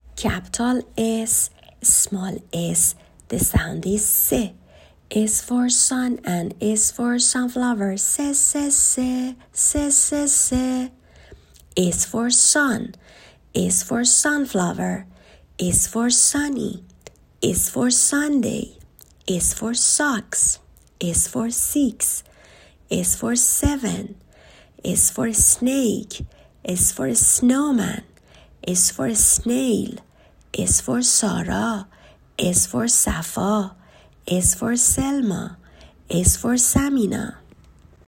حروفی که بچه ها یاد گرفتند تا اینجا رو در قالب چند ویس ، گذاشتم.
حرف Ss ، صداش و لغاتش